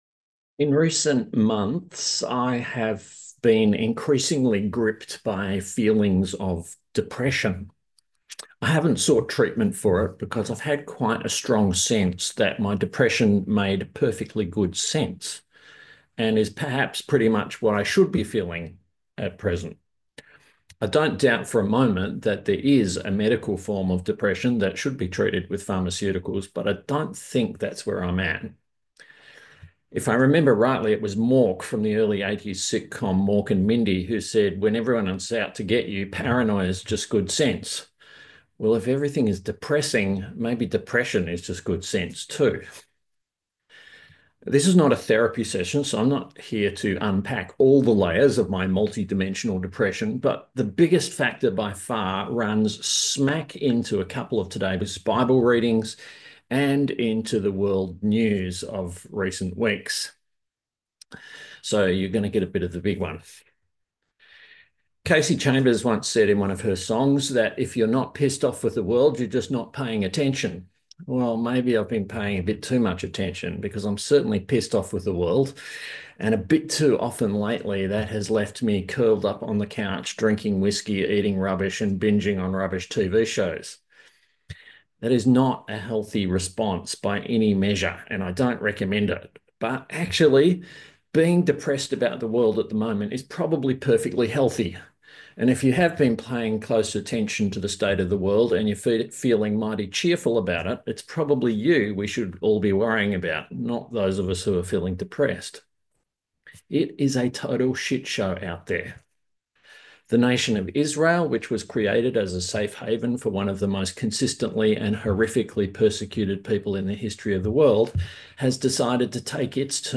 A sermon on Mark 13:1-8 & 1 Samuel 2:1-10